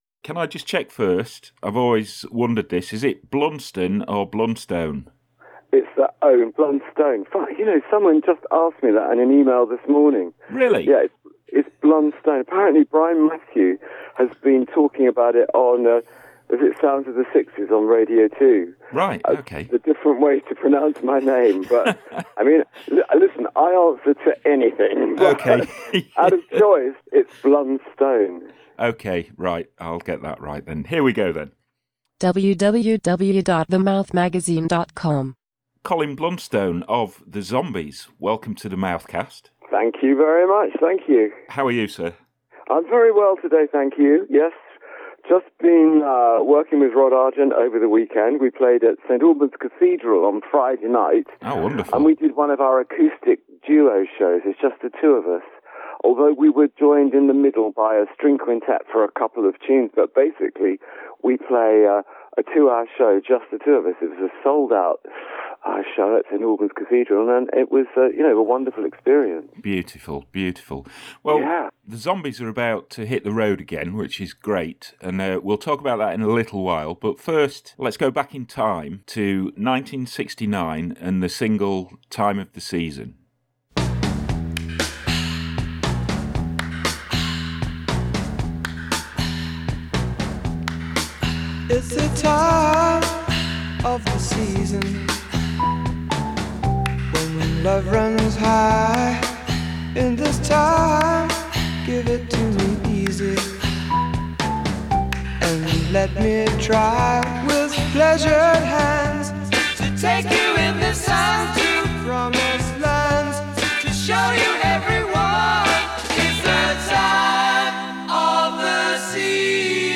IN THIS CHEERFUL NEW EDITION OF THE MOUTHCAST COLIN BLUNSTONE DISCUSSES BEING PART OF THE BRITISH BEAT INVASION OF AMERICA IN THE EARLY TO MID-SIXTIES.